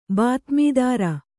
♪ bātmīdāra